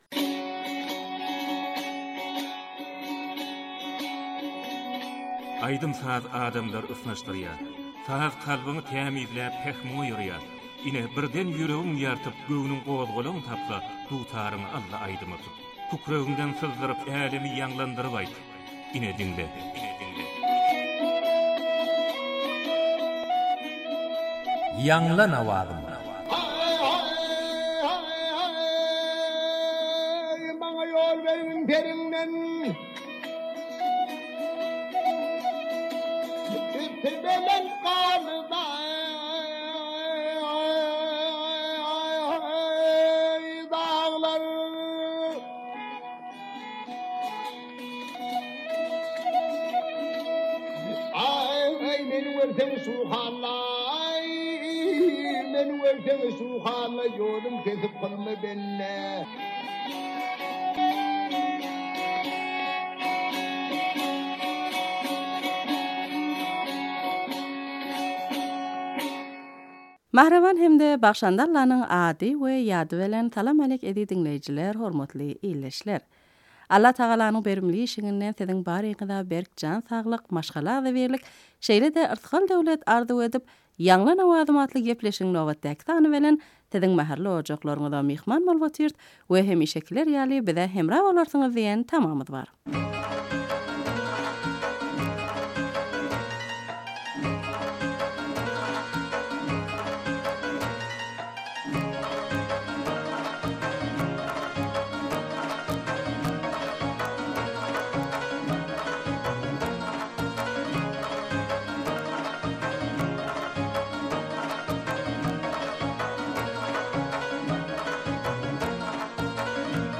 turkmen goşgy aýdym